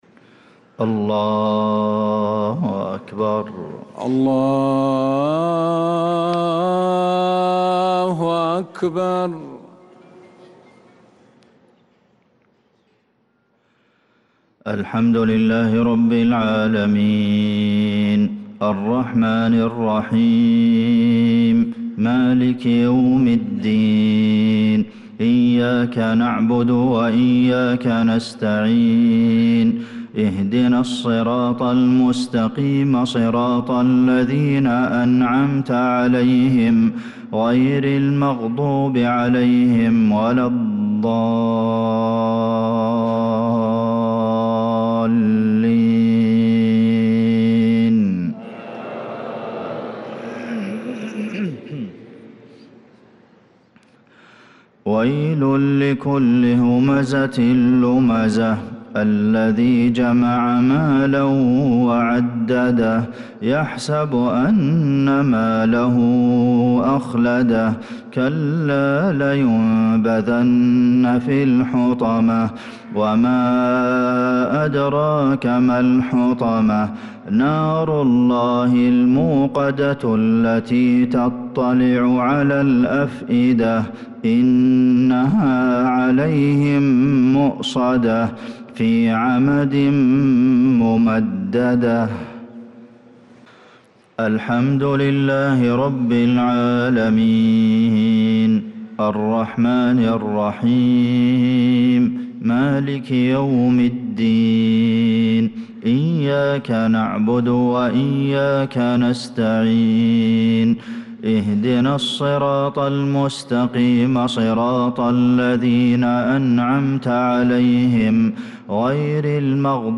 صلاة المغرب للقارئ عبدالمحسن القاسم 19 ربيع الآخر 1446 هـ
تِلَاوَات الْحَرَمَيْن .